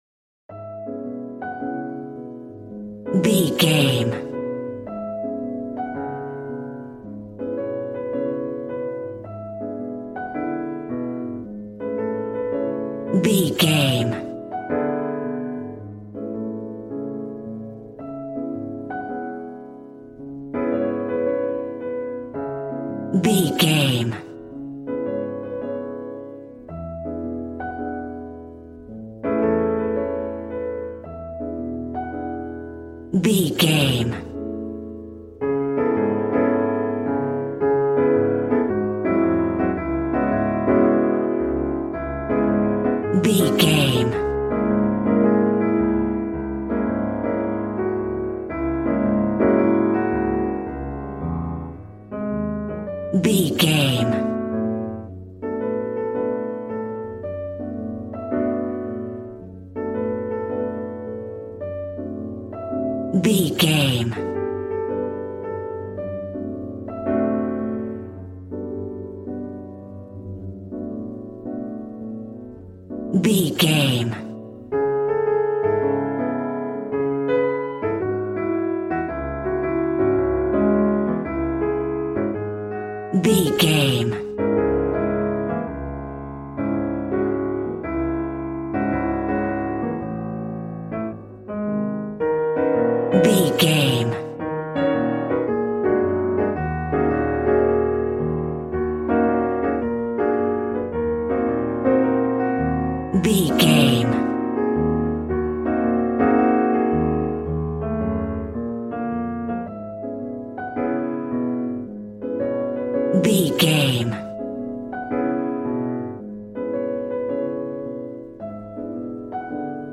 Aeolian/Minor
D♭
piano
drums